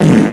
key-press-6.wav